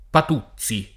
[ pat 2ZZ i ]